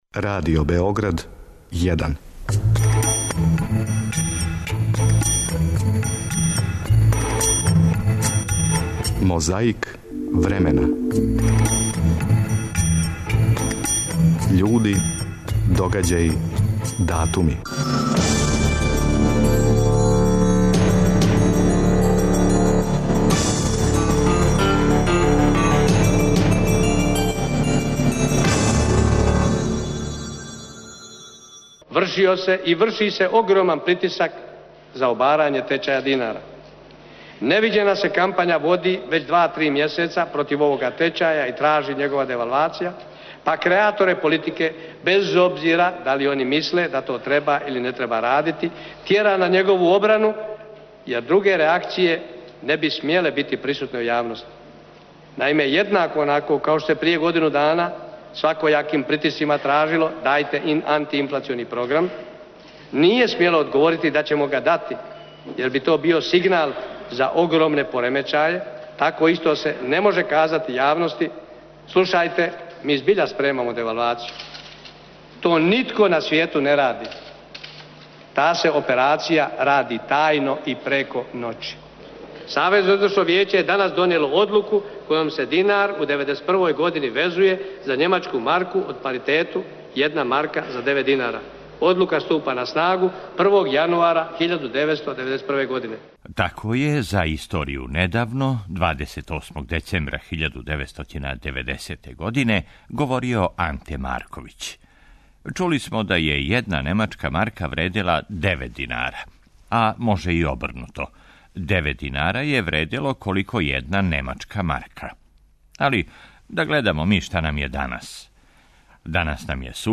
Чућете део програма.
Мозаик времена - борба против пилећег памћења, емисија Првог програма Радио Београда која је почела са радом октобра 2001. године.